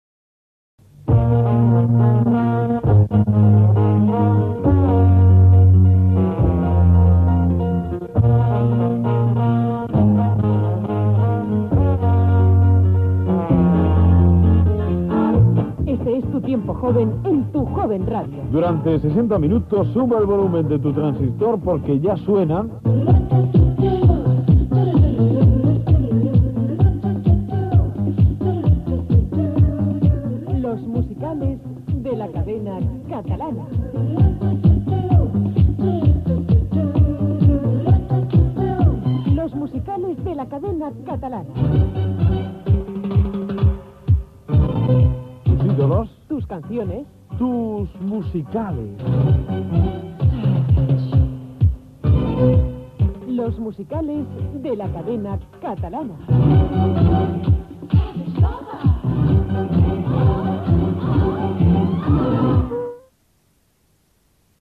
Falca de promoció
Musical